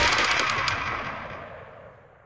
ciwsSpindown.ogg